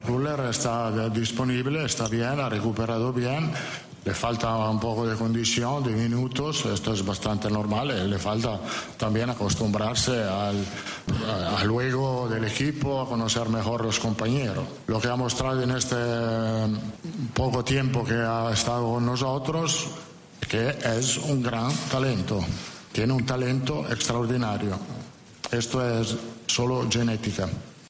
En esa rueda de prensa, Ancelotti quiso destacar a uno de sus jugadores del que dijo que "tiene un talento extraordinario, y esto es solo genética". En el siguiente audio podrás descubrir de quién se trata y en palabras del propio Ancelotti.